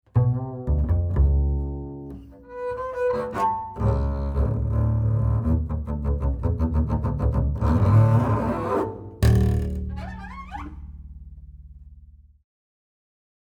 Kontrabass